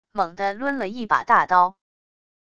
猛地抡了一把大刀wav音频